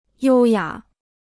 优雅 (yōu yǎ) Kostenloses Chinesisch-Wörterbuch